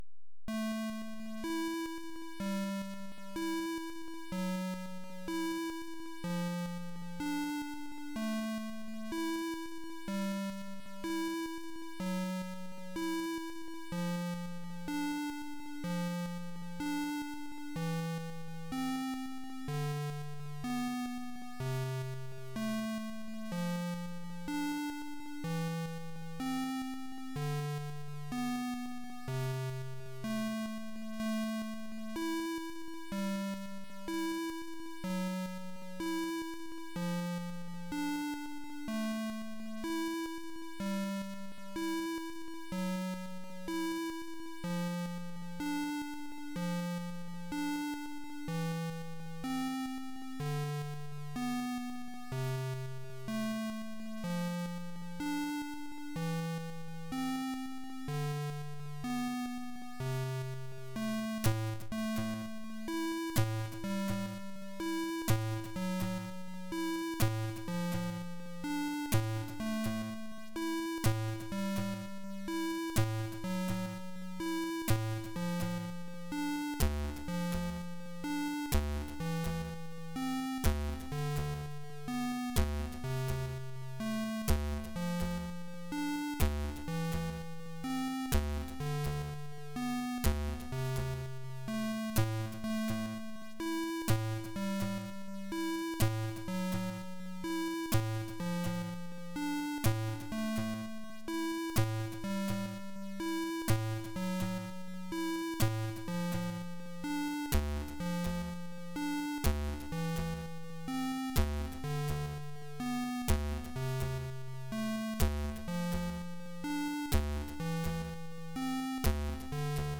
• MOD/Chip/SPC: Sounds best in stereo
• Chip music